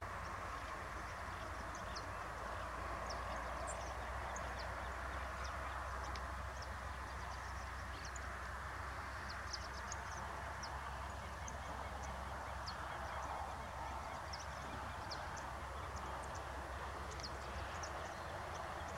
Short-billed Pipit (Anthus furcatus)
Country: Argentina
Province / Department: Entre Ríos
Condition: Wild
Certainty: Recorded vocal